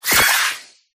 inteleon_ambient.ogg